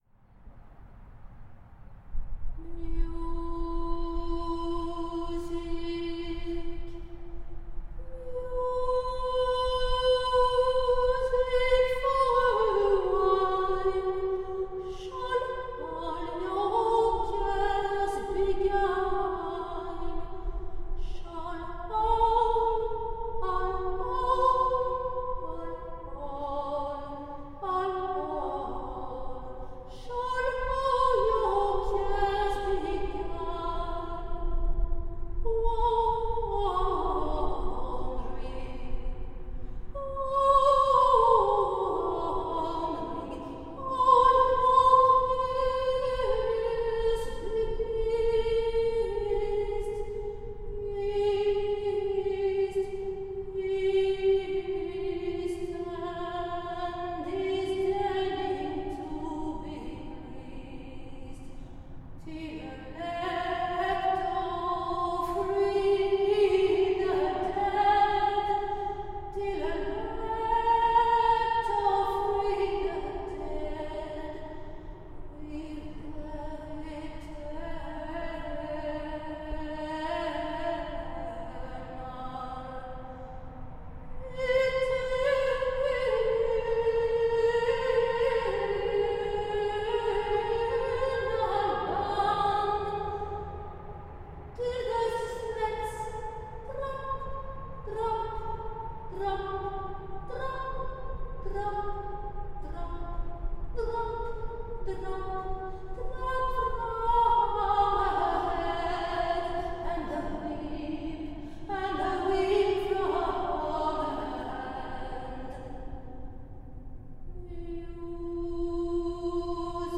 Louisiane, 30 août 2015… au bout des terres qui s’immergent, un pylône surélevé. Dedans, du vent, un chant — et un dauphin vient à la rencontre des alligators…